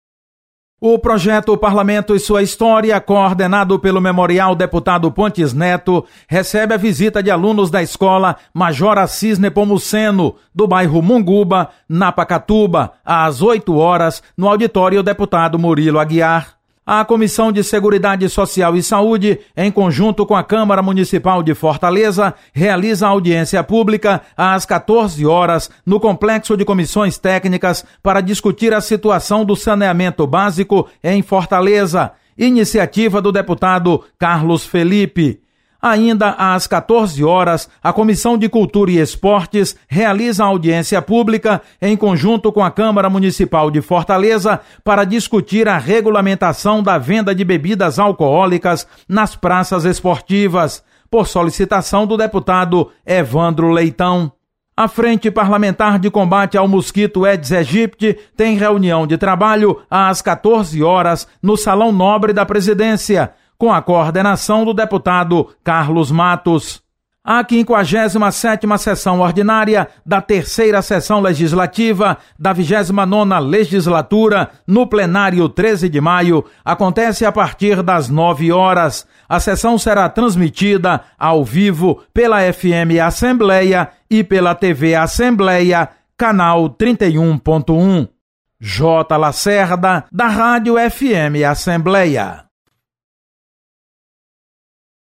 Acompanhe as atividades da Assembleia Legislativa nesta quarta-feira (24/05). Repórter